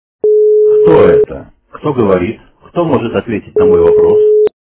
При прослушивании Голос мужчины - Кто это? Кто может ответить на мой вопрос? качество понижено и присутствуют гудки.
Звук Голос мужчины - Кто это? Кто может ответить на мой вопрос?